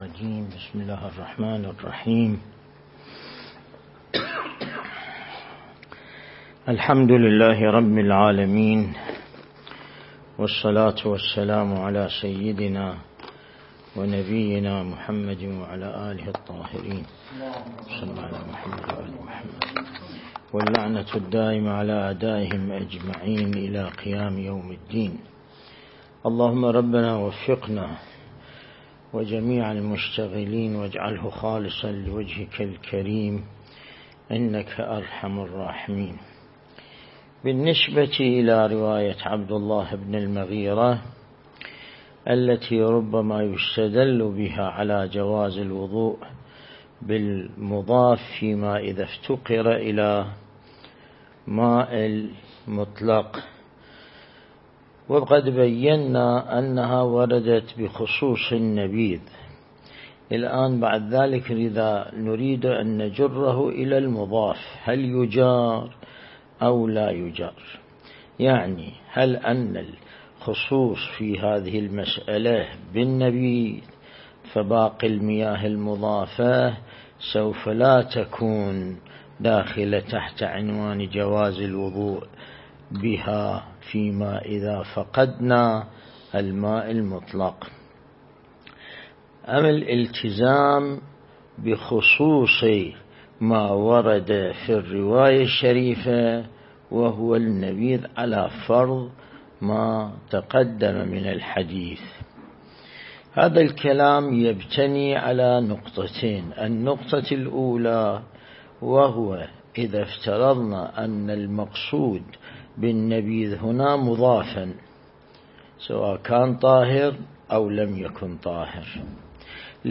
درس (28)